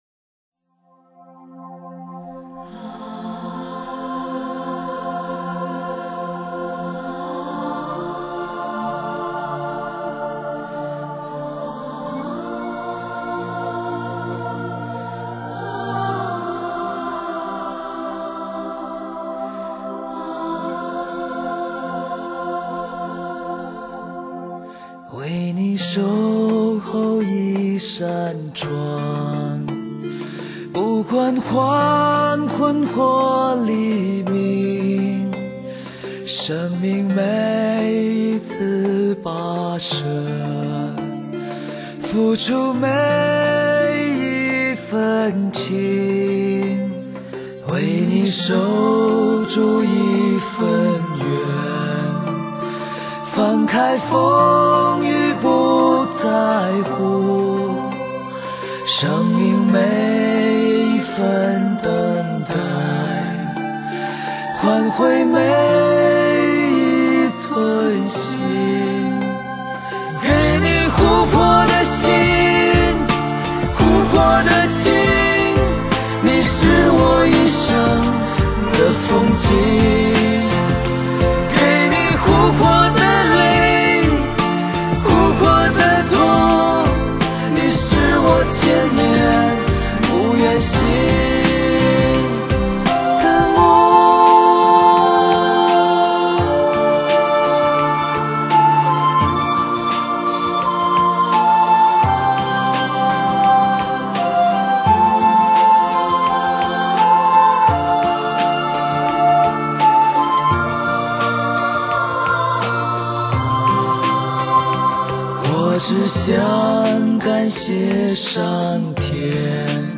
恢弘的管弦乐、时尚的电子乐、张扬的硬摇滚、朴素的